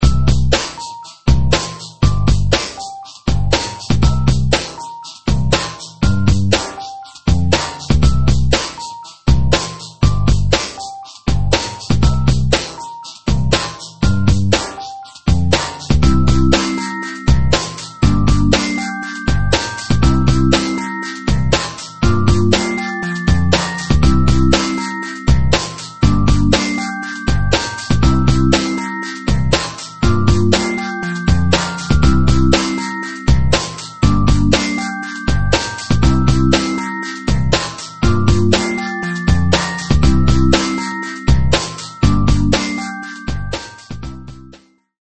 2005 harmonic medium instr.